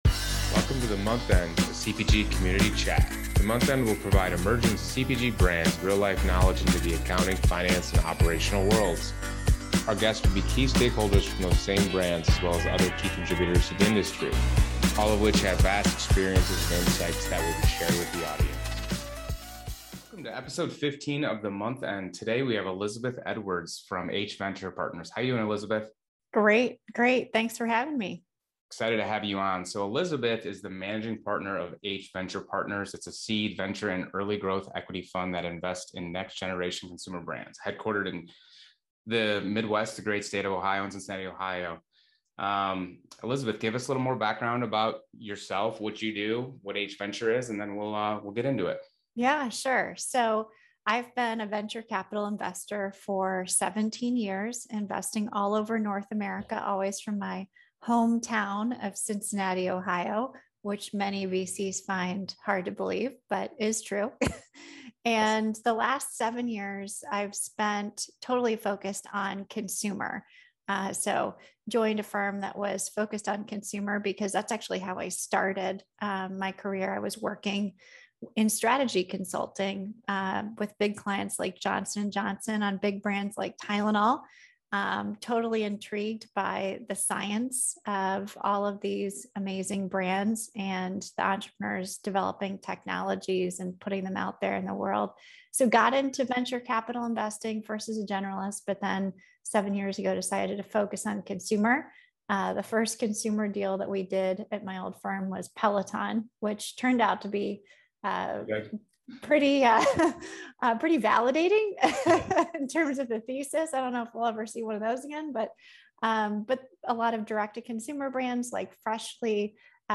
Each episode provides a glimpse into the vast experience and insight from its guest’s unique background in a casual, conversational tone.